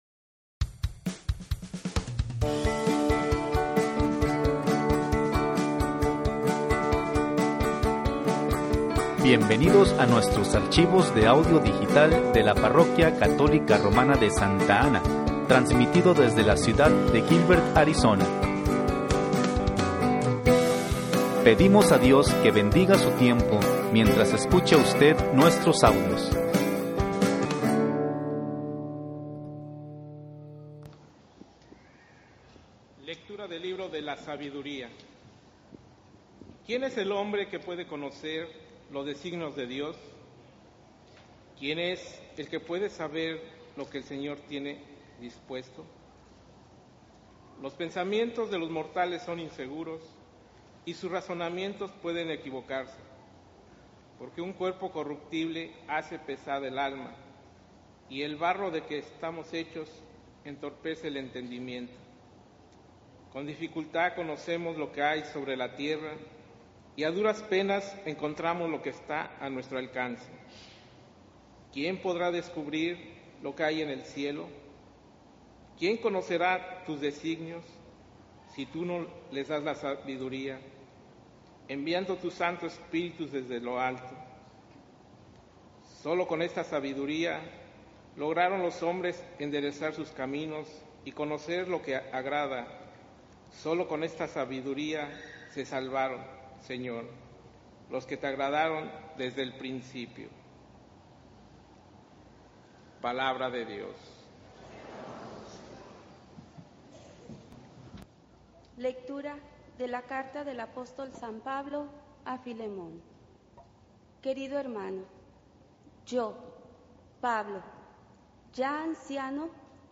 XXIII Domingo Tiempo Ordinario (Lecturas)